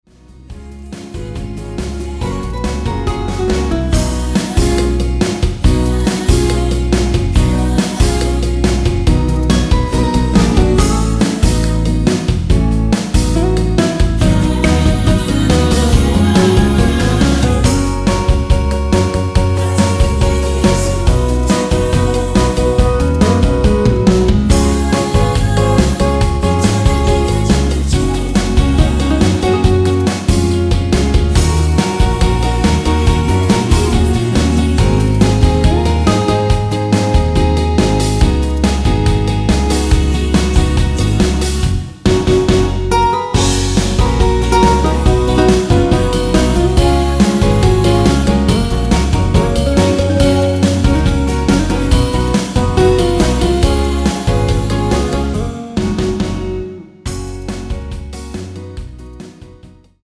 원키에서(-2)내린 코러스 포함된 MR입니다.(미리듣기 참조)
F#
앞부분30초, 뒷부분30초씩 편집해서 올려 드리고 있습니다.
중간에 음이 끈어지고 다시 나오는 이유는